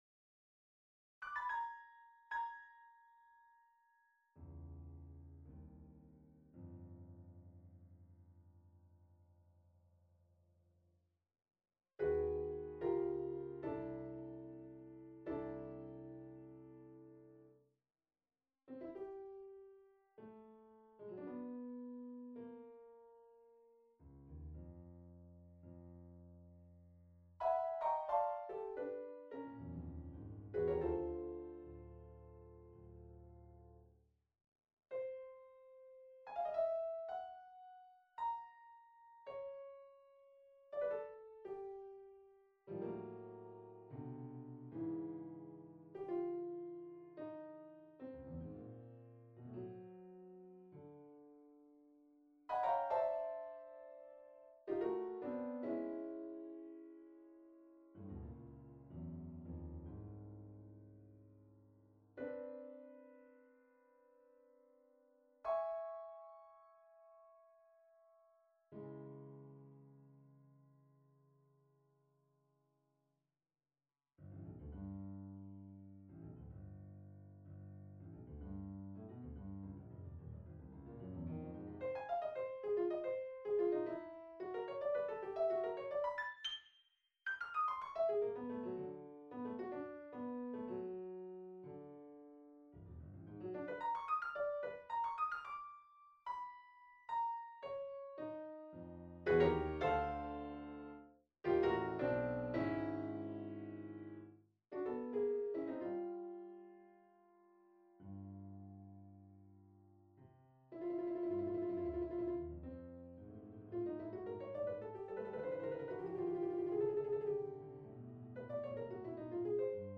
Sonata for Piano No.4, Op.60
Sonata for Piano No.4 on a purpose-selected tone row Op.60 1. Andantino semplice - Allegro ma non troppo - Allegro assai - Poco a poco decelerando al...Adagietto 2. Rondo. Adagio - Adagietto - Andante - Moderato - Allegro - Allegro molto - Presto molto - Prestissimo - Poco a poco decelerando al...Adagio Date Duration Download 24 November 2016 16'46" Realization (.MP3) Score (.PDF) 23.0 MB 228 KB